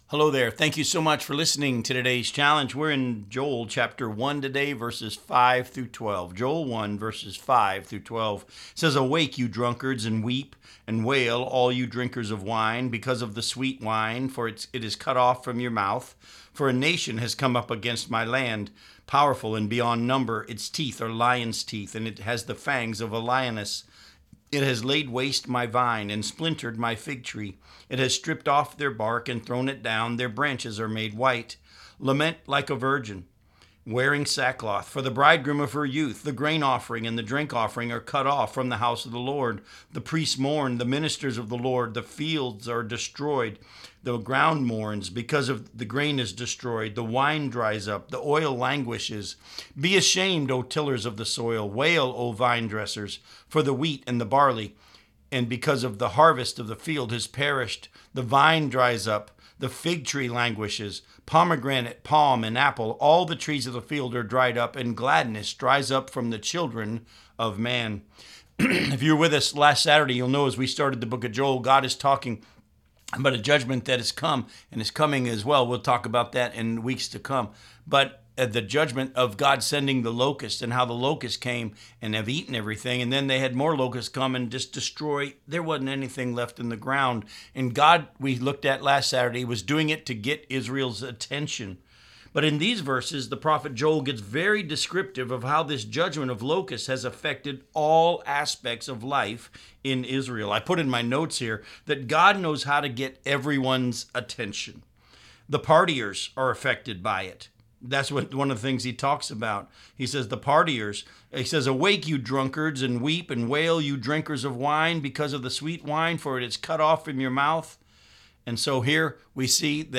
Challenge for Today Radio Program